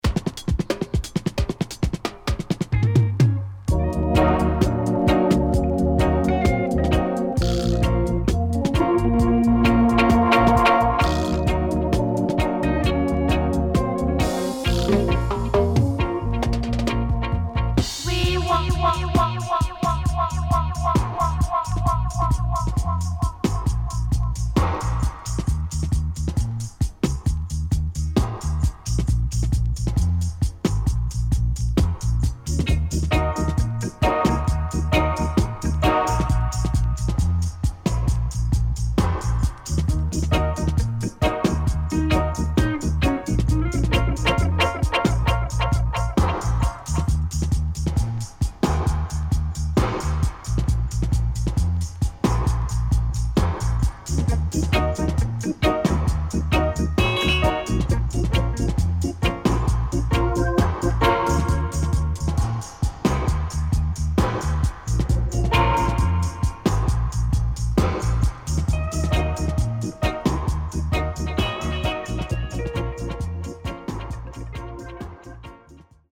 HOME > DUB